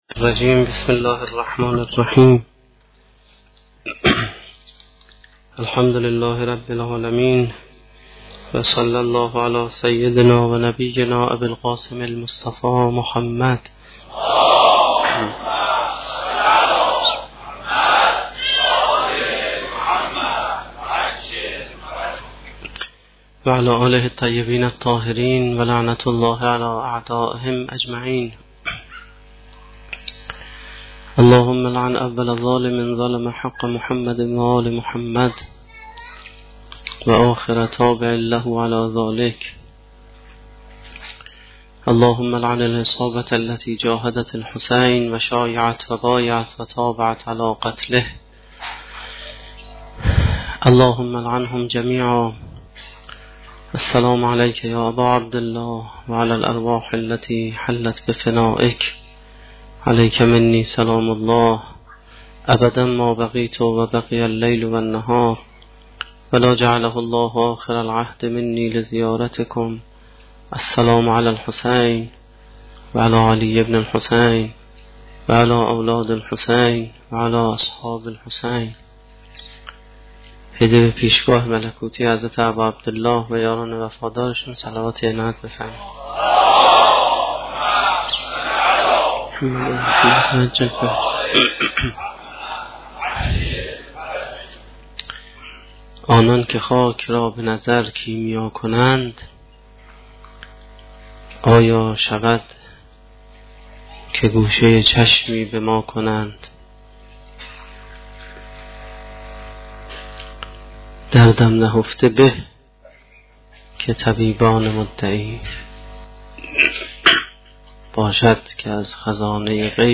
سخنرانی نهمین شب دهه محرم1435-1392